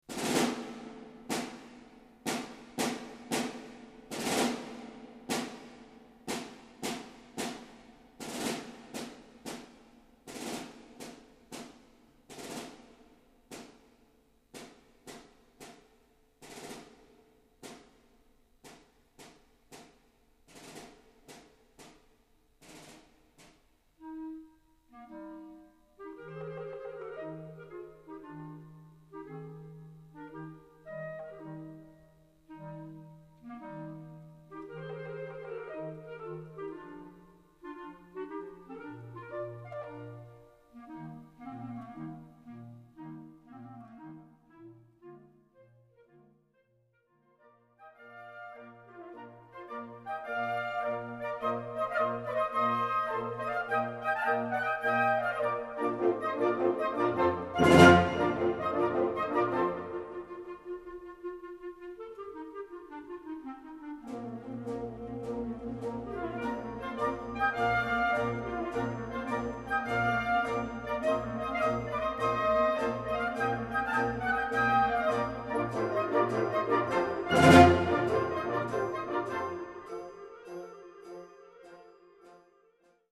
Recueil pour Harmonie/fanfare - Concert Band